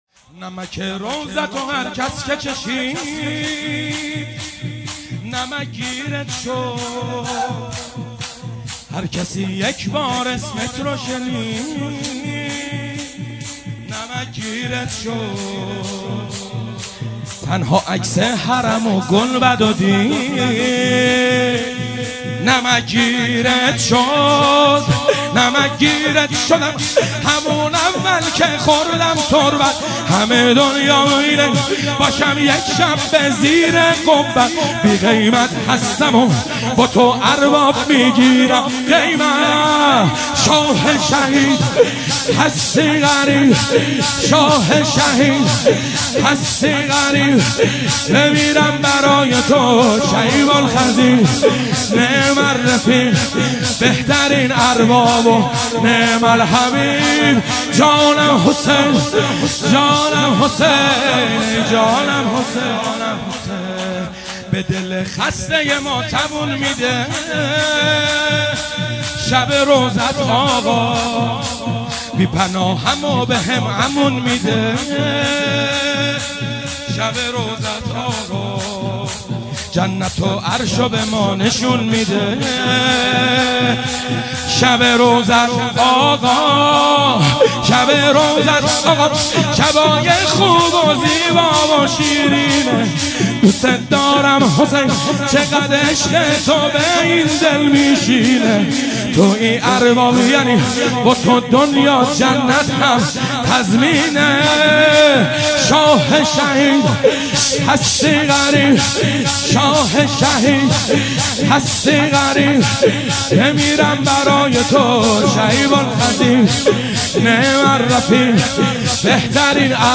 نمک روضه
هیئت جنت العباس (ع) کاشان